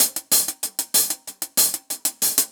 Index of /musicradar/ultimate-hihat-samples/95bpm
UHH_AcoustiHatC_95-04.wav